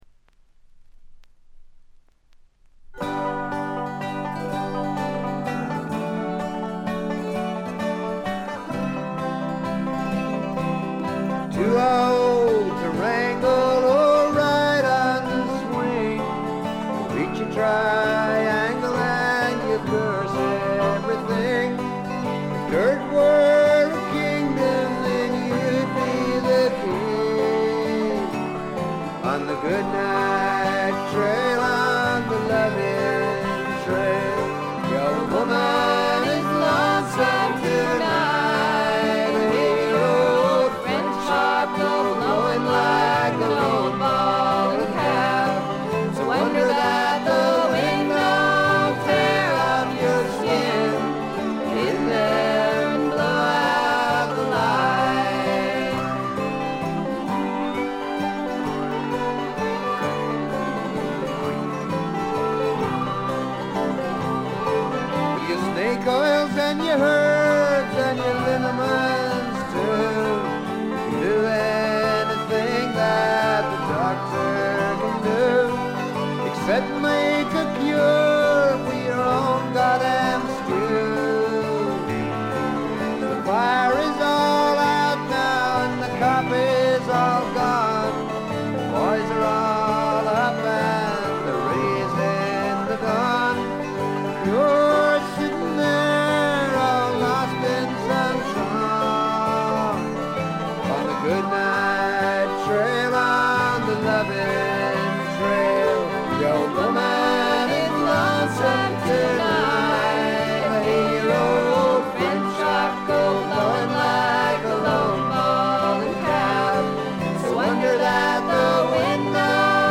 わずかなノイズ感のみ。
録音もベルギーで行われており、バックのミュージシャンも現地組のようです。
試聴曲は現品からの取り込み音源です。
Vocals, Backing Vocals, Guitar, Harmonica